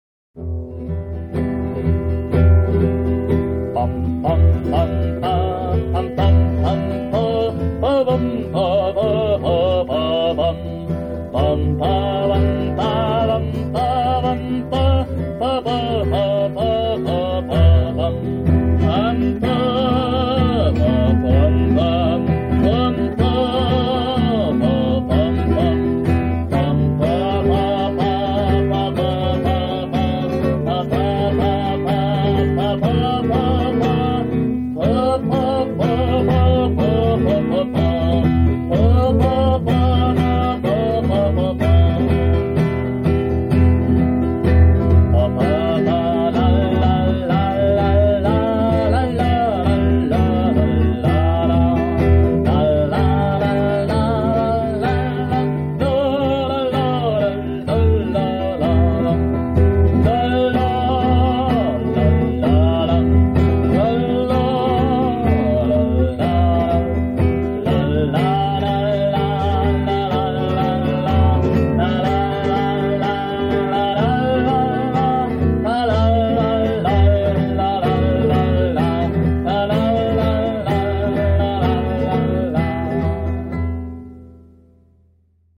Melodie-Notiz von 1977 ohne Worte